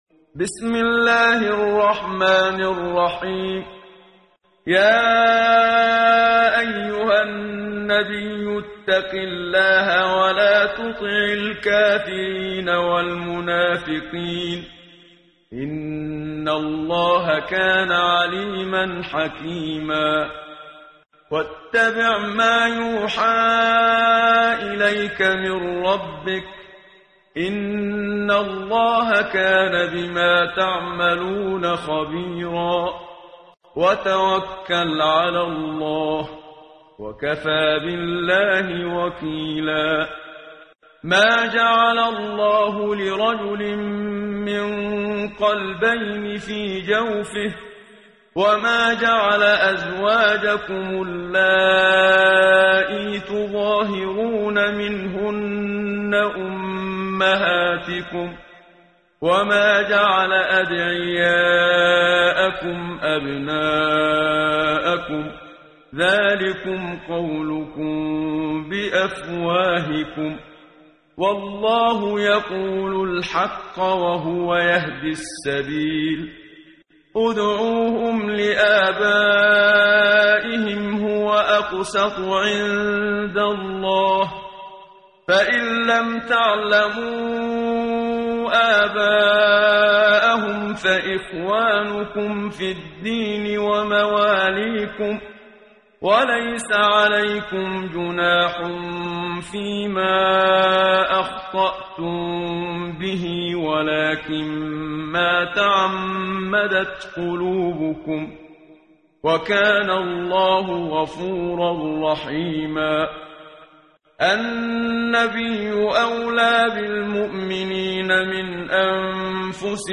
قرائت قرآن کریم ، صفحه 418، سوره مبارکه الاحزاب آیه 1 تا 6 با صدای استاد صدیق منشاوی.